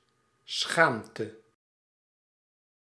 Ääntäminen
Ääntäminen Tuntematon aksentti: IPA: /ˈsxaːm.tə/ Haettu sana löytyi näillä lähdekielillä: hollanti Käännös Ääninäyte Substantiivit 1. honte {f} France Muut/tuntemattomat 2. opprobre {m} Suku: f .